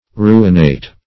Ruinate \Ru"in*ate\, v. i.